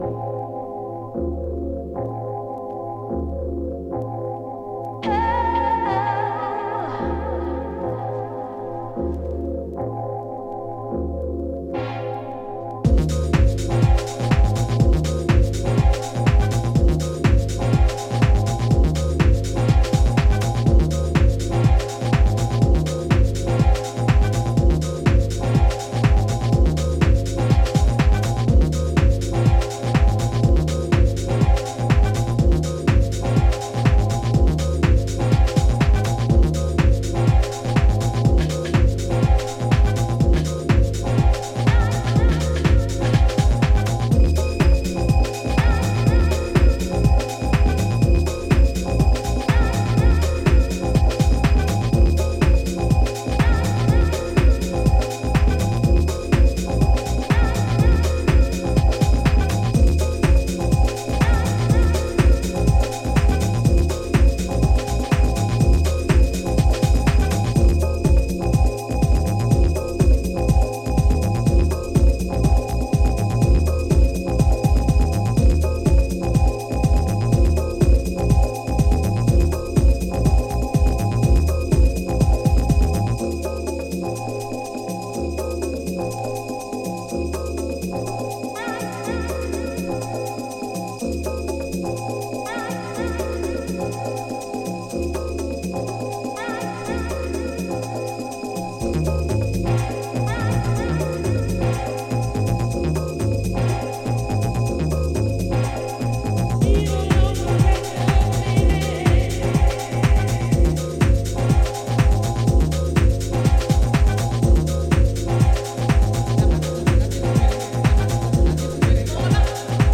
今回もシルキーでメロディアスなシンセワークやアナログマシンのダスティなグルーヴを駆使したディープ・ハウスを展開。